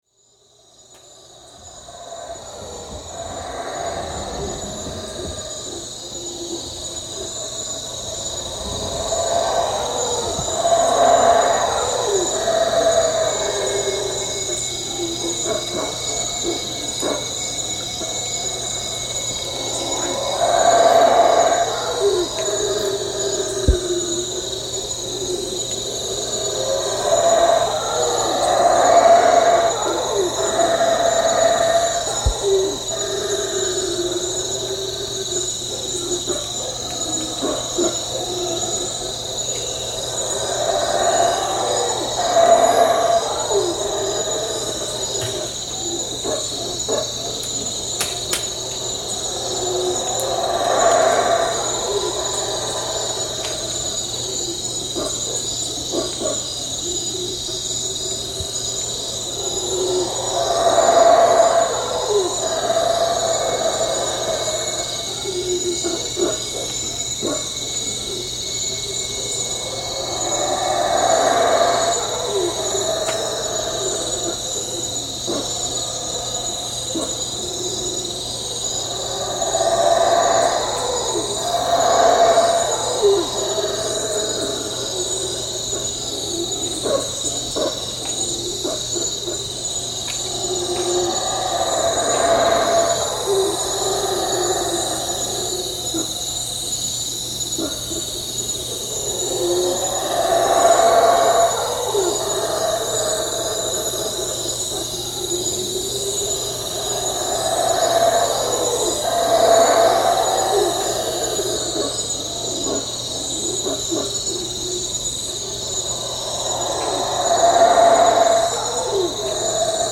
Howler Monkeys-Belize
alouatta-pigra aloutta animal ape Belize call calls field-recording sound effect free sound royalty free Animals